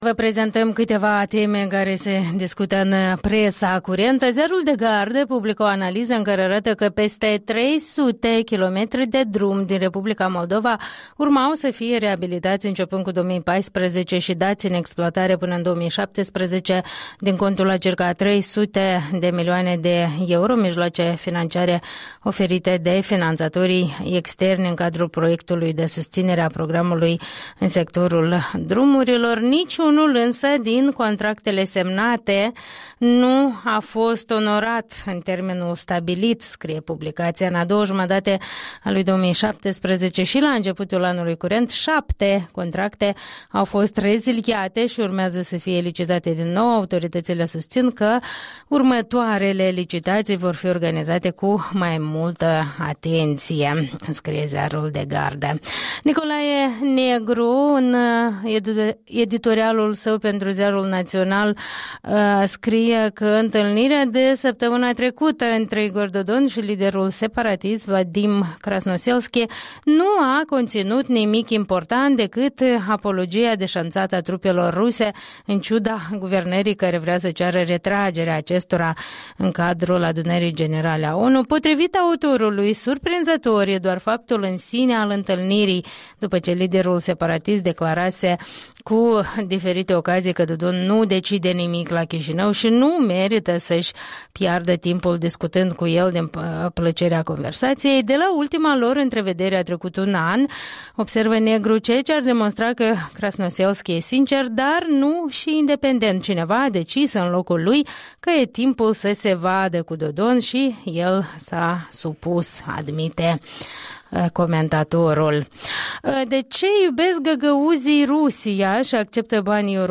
Revista presei